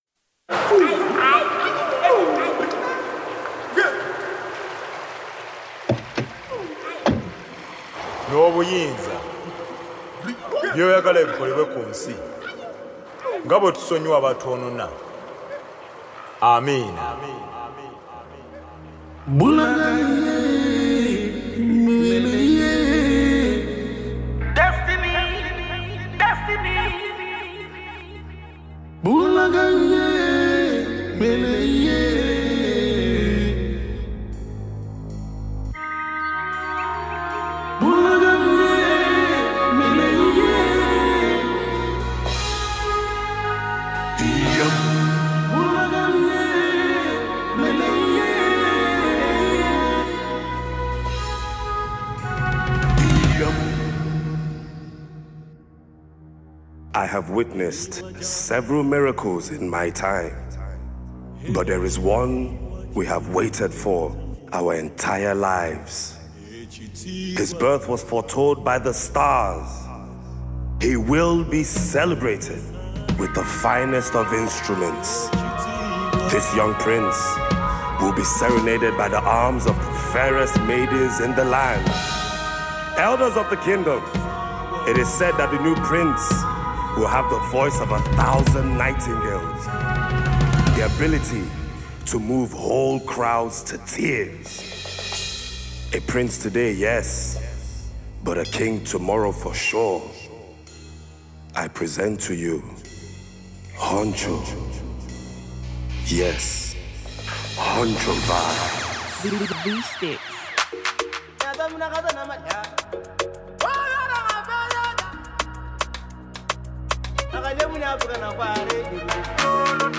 classical single